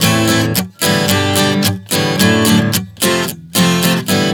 Prog 110 Am-G-D-F.wav